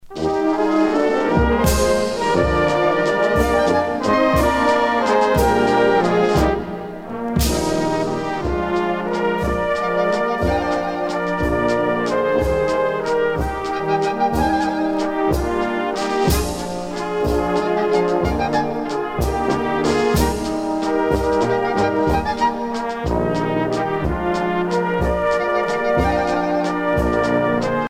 danse : valse